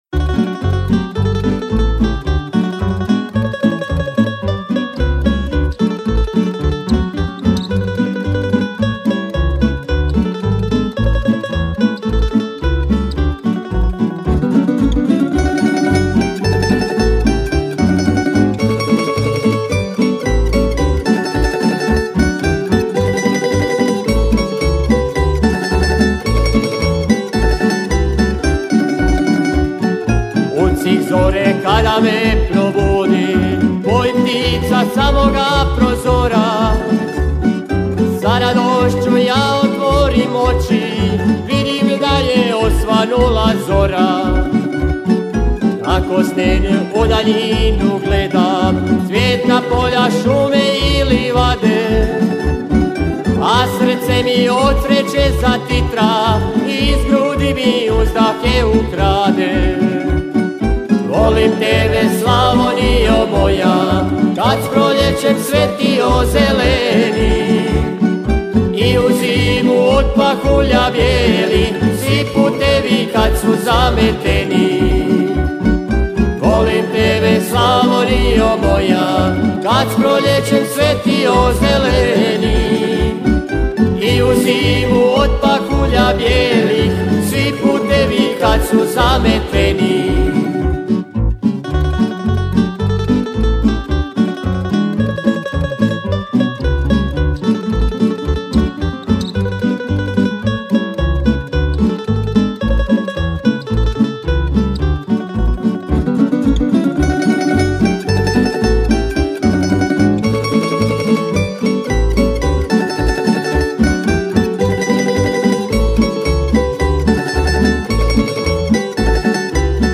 Na festivalu je ove godine nastupilo 19 izvođača s novim autorskim pjesmama, od toga je bilo 8 tamburaških sastava te 11 solista.